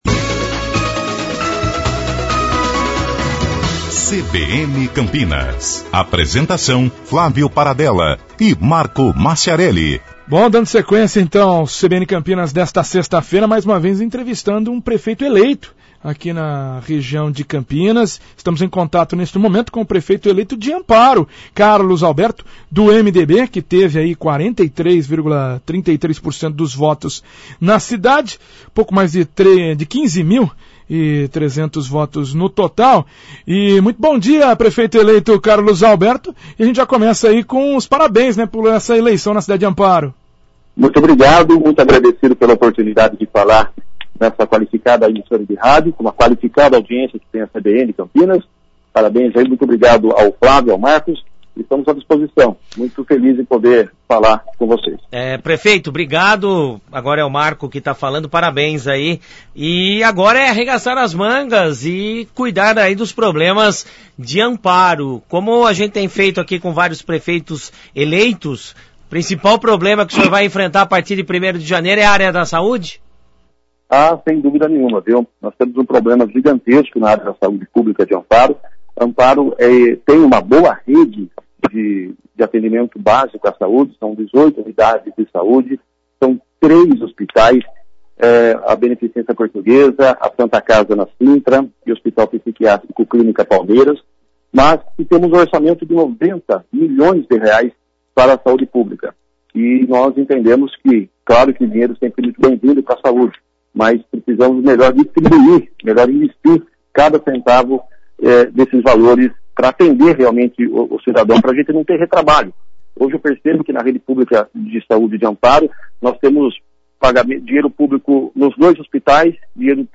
Prefeito eleito de Amparo, Carlos Alberto fala sobre seus desafios para conduzir a cidade